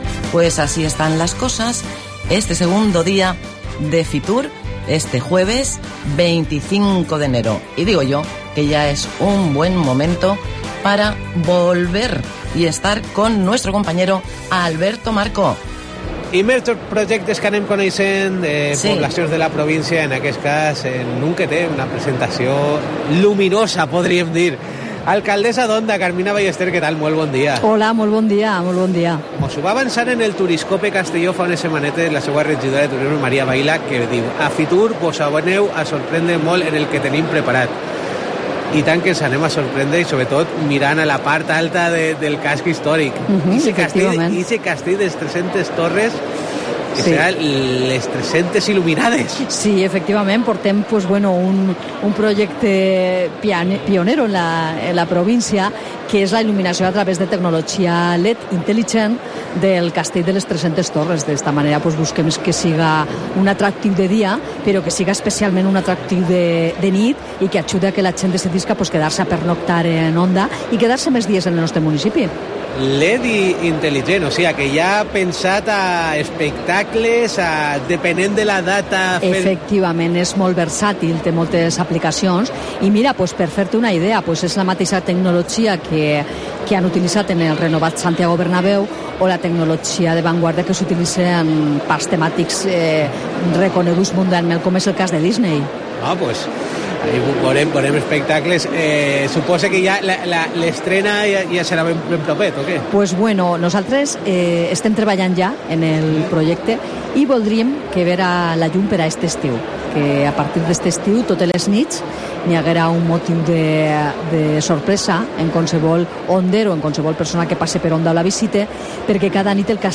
La alcaldesa de Onda, Carmina Ballester, hace parada por los micrófonos de COPE para presentar en FITUR el espectáculo de luces del Castillo de las 300 Torres como nuevo atractivo turístico. Un innovador proyecto de iluminación para el Castillo de Onda que busca transformar la emblemática fortaleza en un espectáculo visual único.